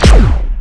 fire_laser4.wav